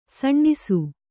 ♪ saṇṇisu